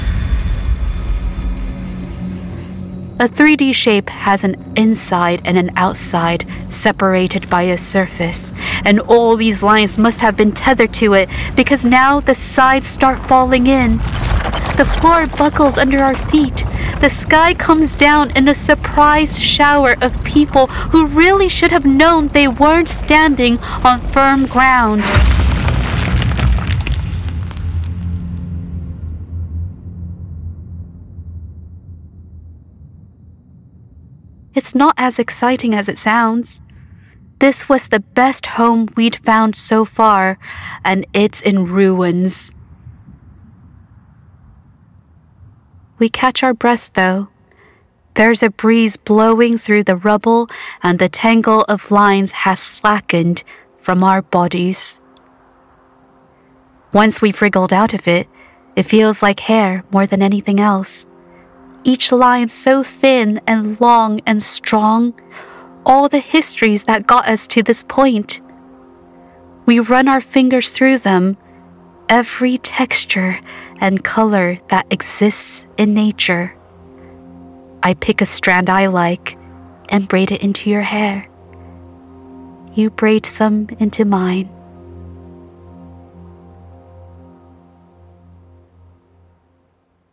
East Asian Accent Showreel
Female
Bright
Warm